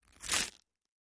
ho_fleshtear_skull_02_hpx
Human flesh being ripped and torn from skull. Mutilation, Body Dismemberment, Gore Tear, Flesh